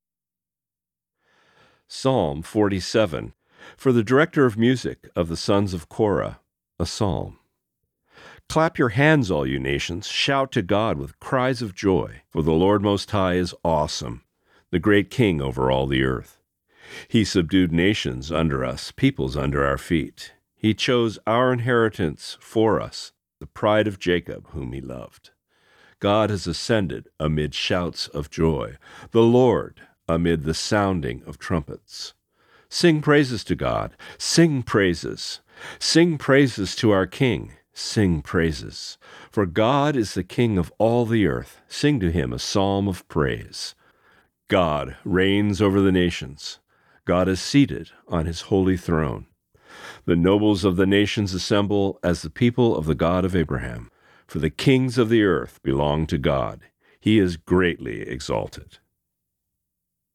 Reading: Psalm 47